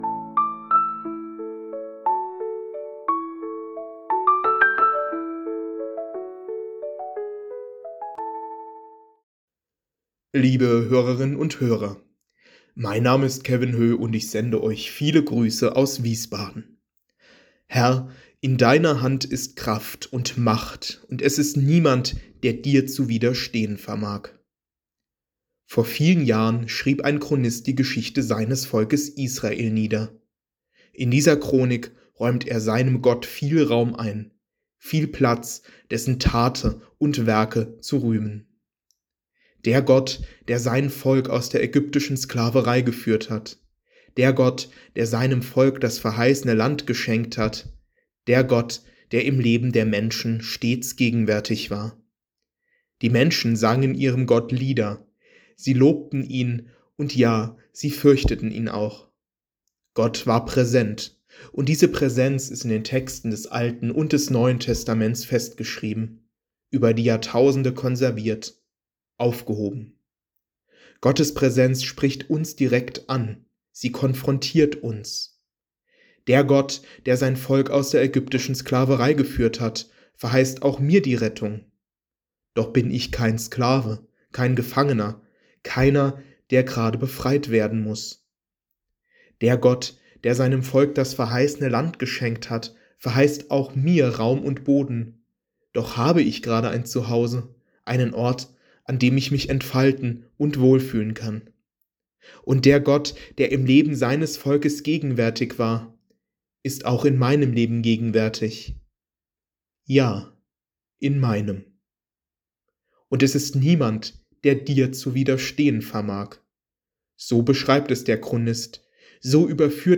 Losungsandacht für Samstag, 07.06.2025